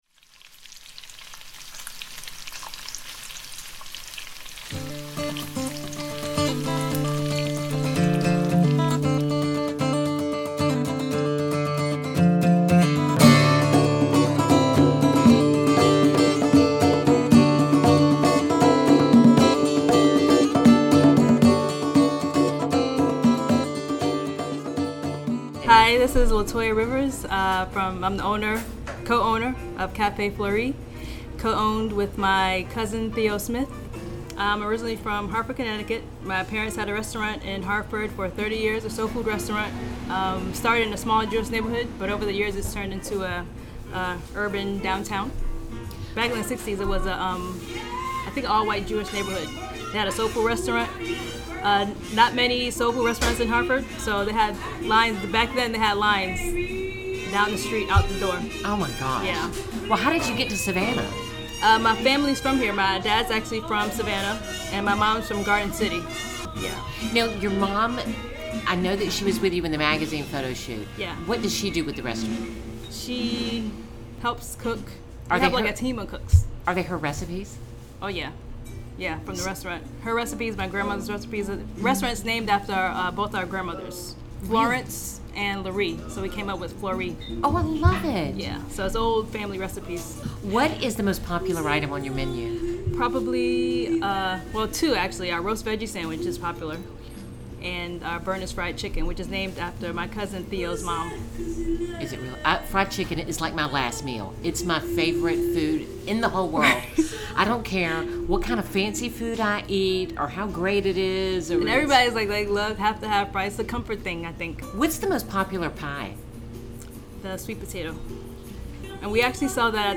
Her voice is as thoughtful as her menu. Listen Up, you'll love her giggle.